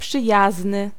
Ääntäminen
Ääntäminen France: IPA: [a.mi.kal] Haettu sana löytyi näillä lähdekielillä: ranska Käännös Ääninäyte 1. przyjazny {m} Suku: m .